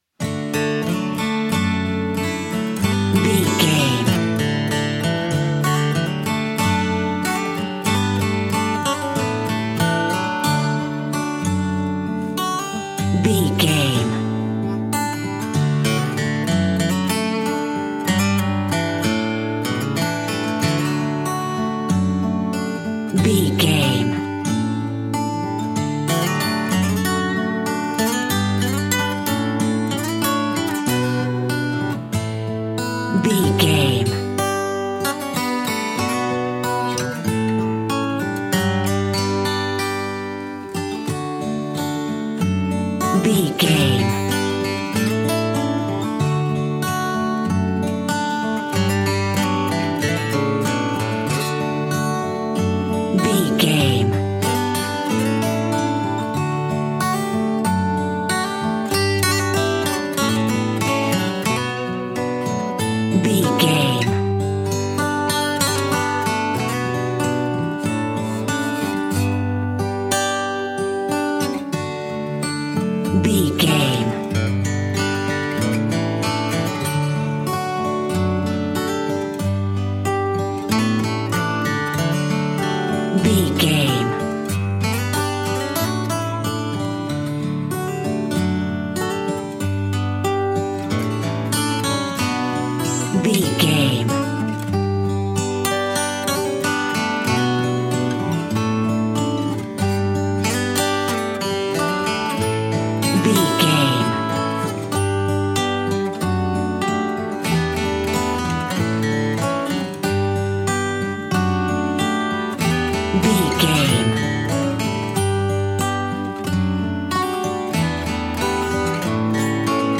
Ionian/Major
Slow
acoustic guitar
bass guitar
Pop Country
country rock
bluegrass
happy
uplifting
driving
high energy